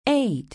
Index of /phonetones/unzipped/LG/KE990-Viewty/Keytone sounds/Piano